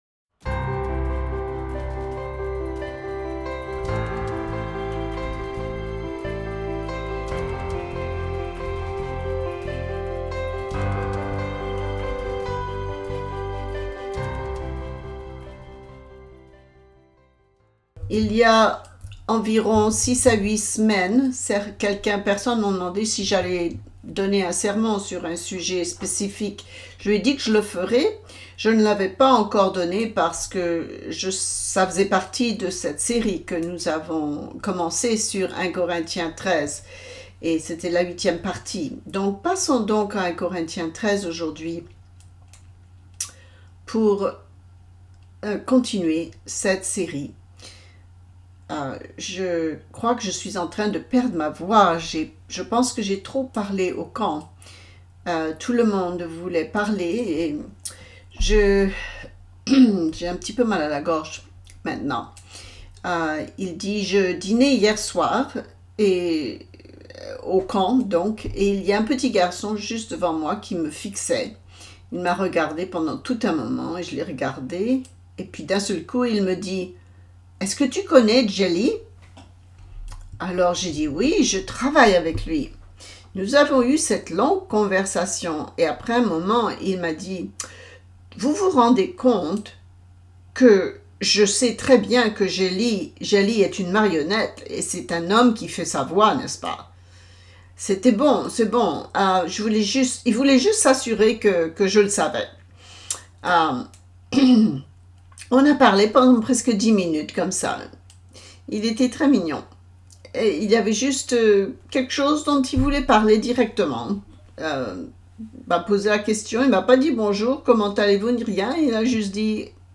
Ce sermon discute et explique le fait que l'amour ne s'irrite pas.